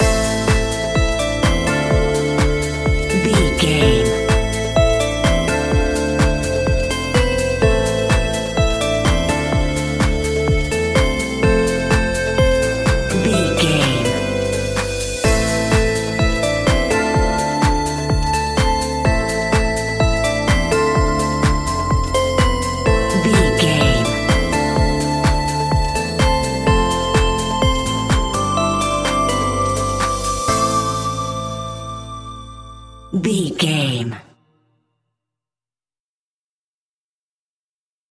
Aeolian/Minor
groovy
uplifting
futuristic
drum machine
synthesiser
bass guitar
funky house
nu disco
upbeat
funky guitar
synth bass